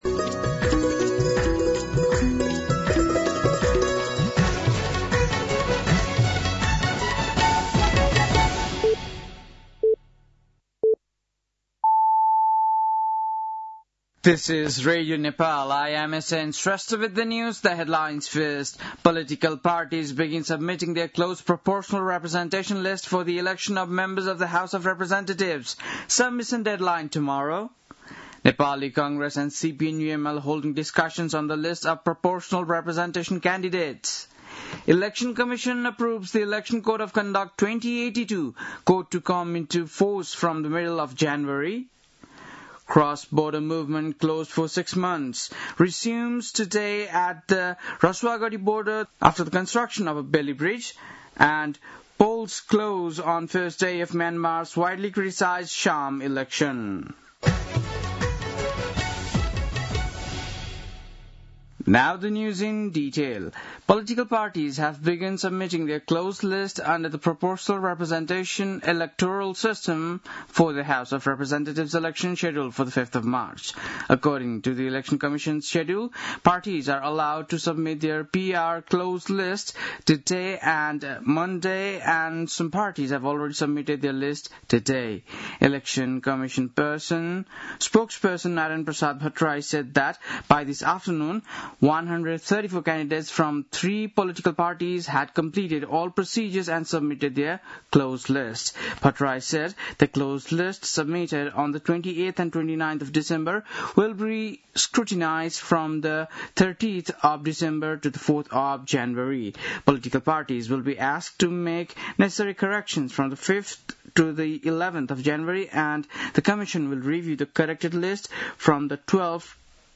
बेलुकी ८ बजेको अङ्ग्रेजी समाचार : १३ पुष , २०८२
8.-pm-english-news-1-3.mp3